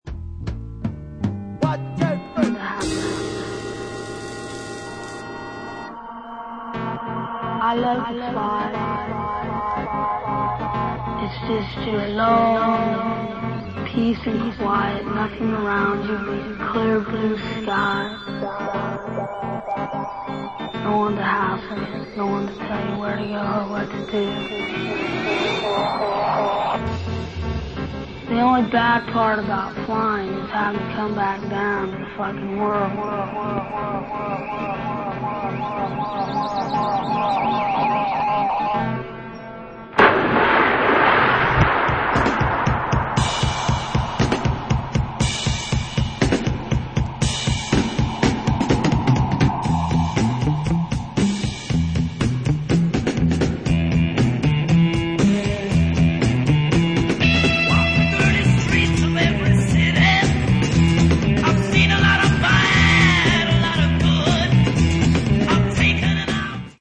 psychedelic DJ
street freak mixes